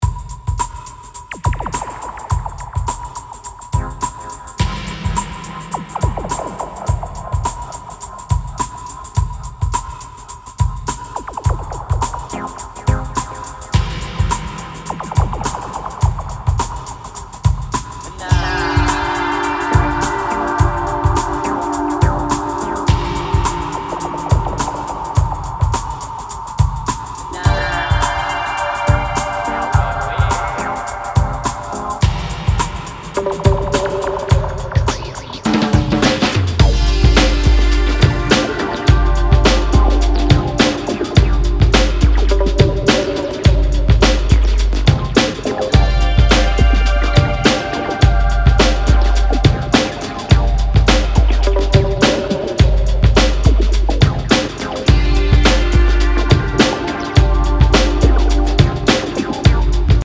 ダビーなエフェクトやゆるいアシッド・シンセが高温多湿なムードを連想させるブレイクビーツ
ハウス、ファンク、エレクトロといった多様なジャンルを行き来する、コミカルかつ躍動感溢れる内容に仕上がっています！